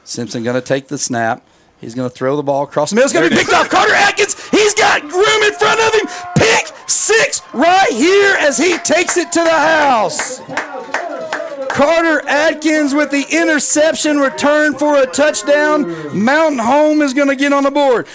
Here’s how it sounded on the Bomber Sports Network.